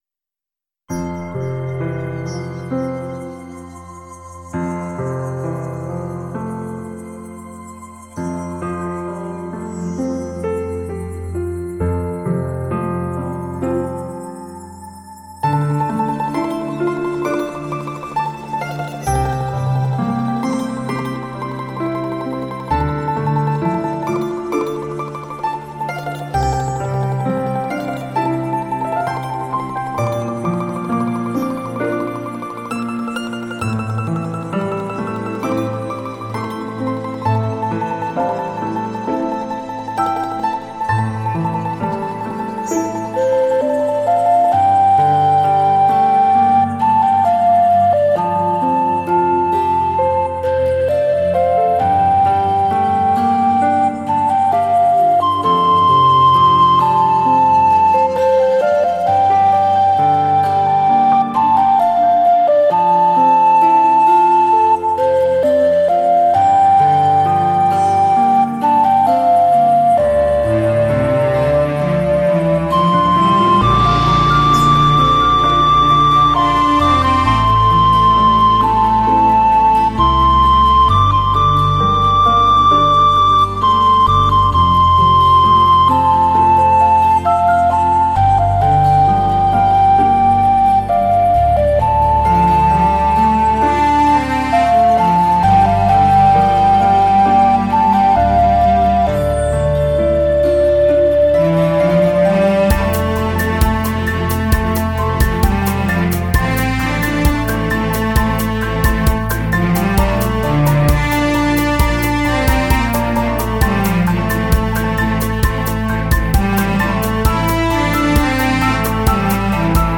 风靡亚洲的陶笛 东方器乐和西方弦乐的完美匹配